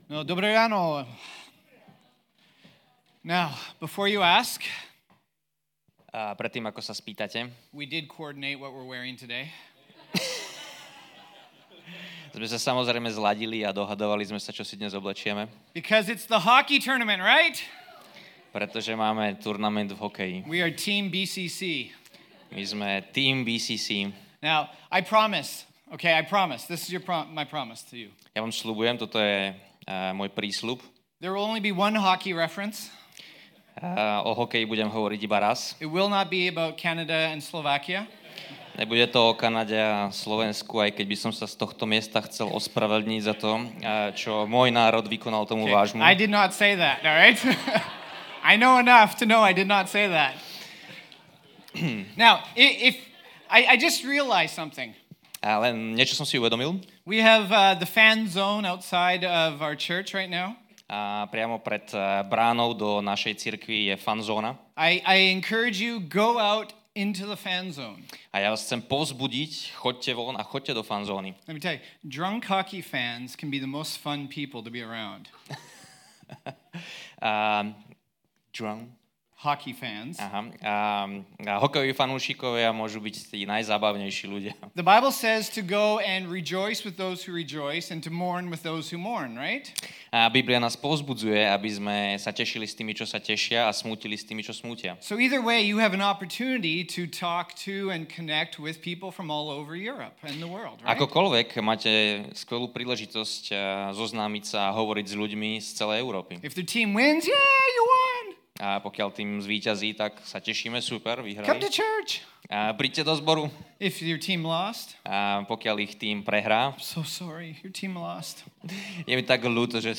Čo to teda znamená byť povolaný von z niečoho? Aj na túto otázku si odpovieme v dnešnej kázni.